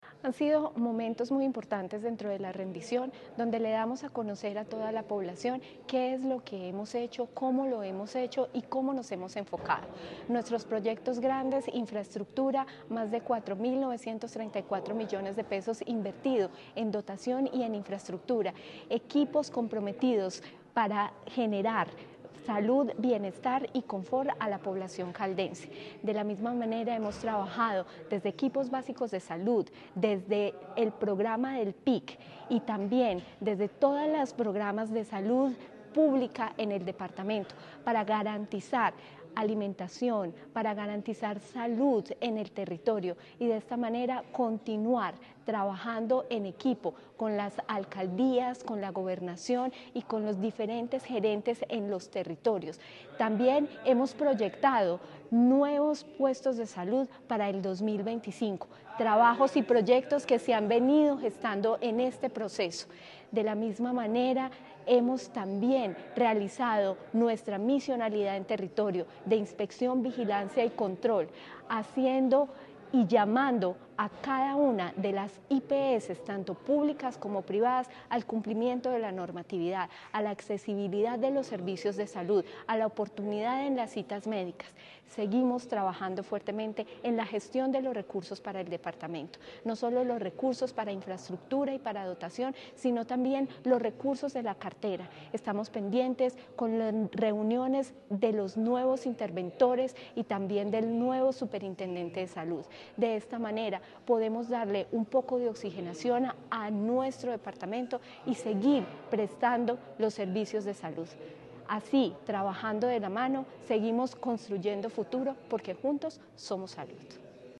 La Dirección Territorial de Salud de Caldas (DTSC) llevó a cabo su Audiencia Pública de Rendición de Cuentas correspondiente a la vigencia 2024, un espacio en el que se destacó el trabajo realizado en pro de la salud y el bienestar de los caldenses.
Natalia Castaño Díaz, directora de la DTSC.